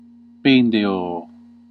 Ääntäminen
IPA : /ˈdɹuːp/ US : IPA : [ˈdɹuːp]